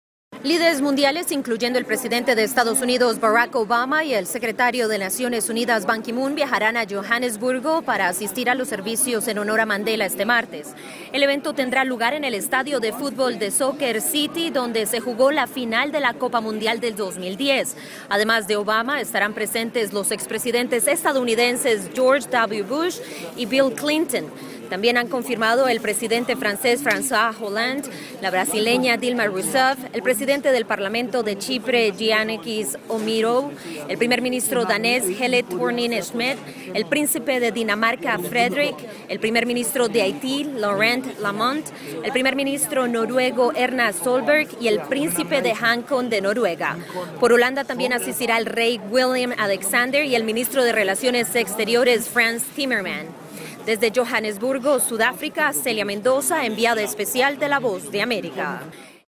desde Johannesburgo